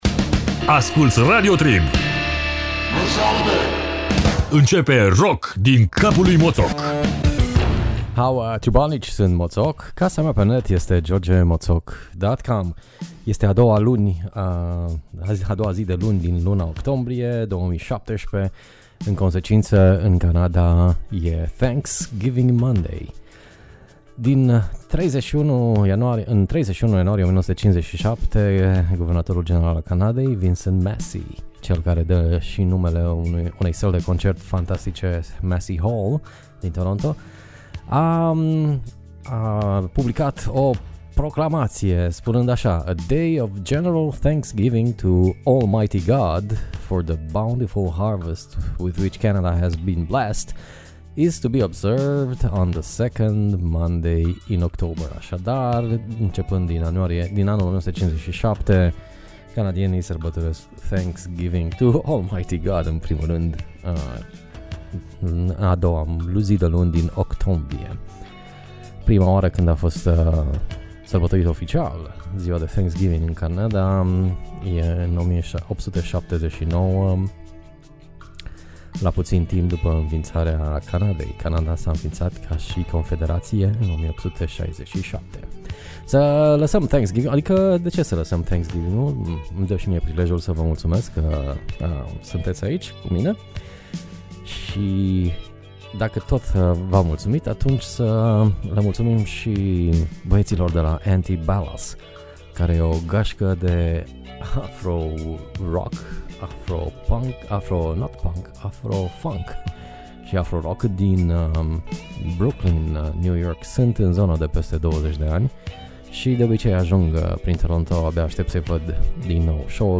Afrobeat si funk made in Brooklyn si Ottawa.